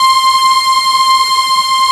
MELLOW C6.wav